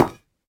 Minecraft Version Minecraft Version latest Latest Release | Latest Snapshot latest / assets / minecraft / sounds / block / netherite / step2.ogg Compare With Compare With Latest Release | Latest Snapshot
step2.ogg